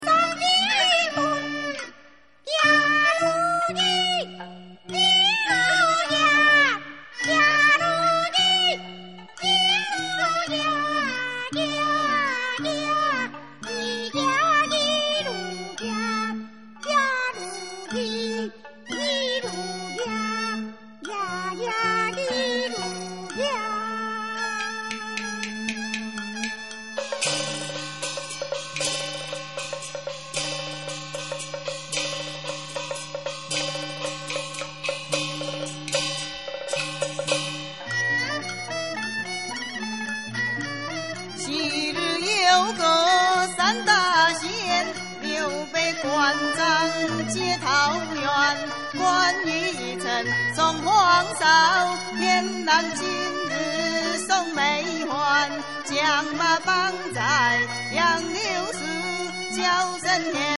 雷神洞【跺子】 北管新路戲曲